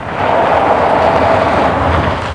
SKID-D2.WAV